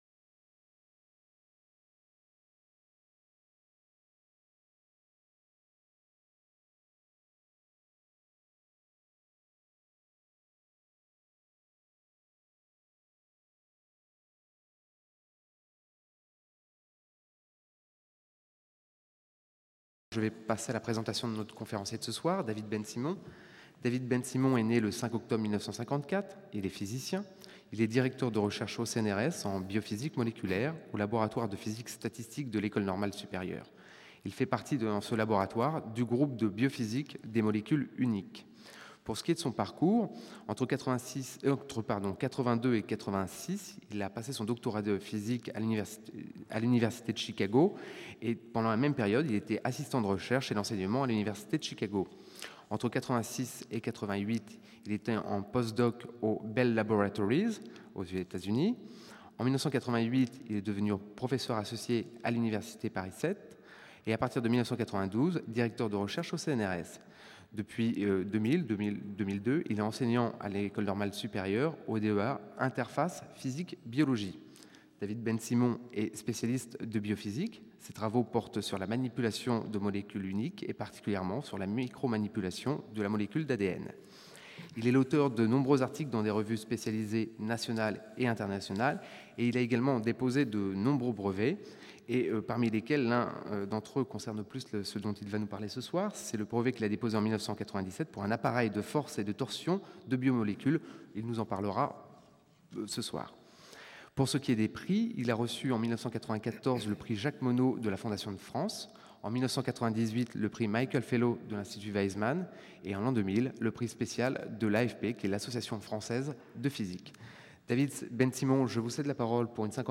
Pas de résumé disponible pour cette conférence